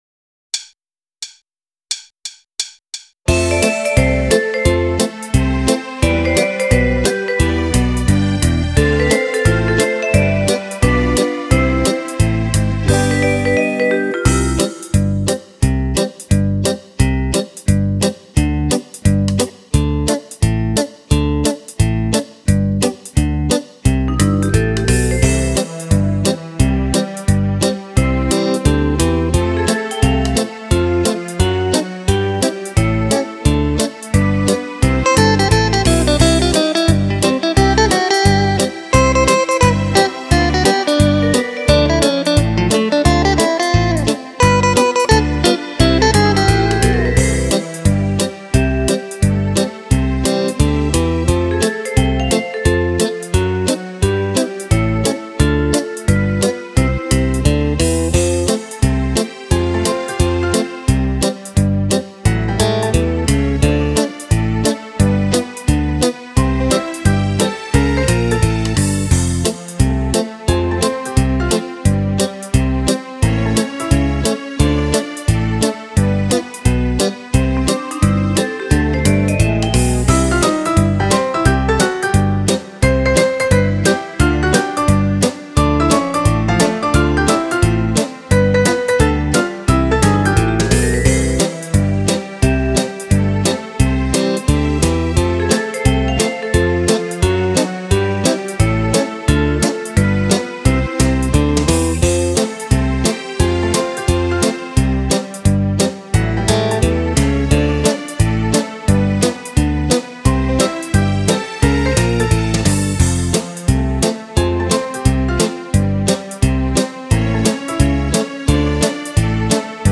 10 ballabili per Fisarmonica
Fox-trot